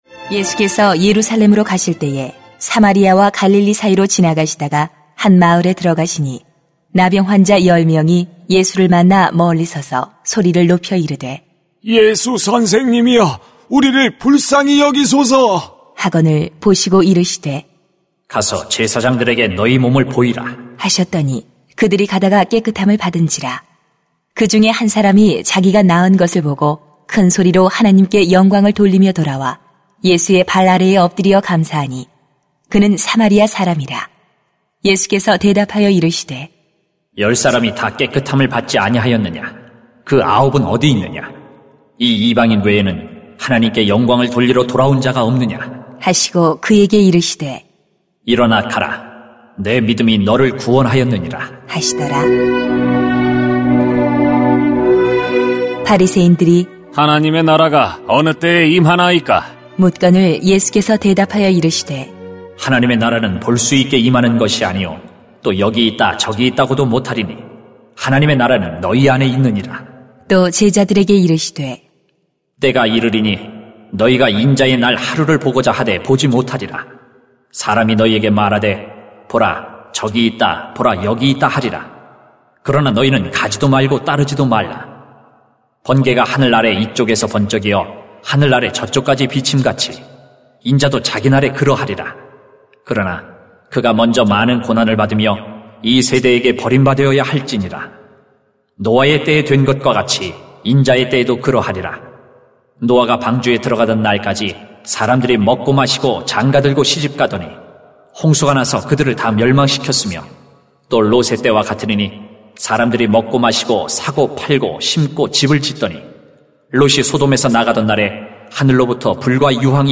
[눅 17:20-37] 예수님이 다시 오십니다 > 새벽기도회 | 전주제자교회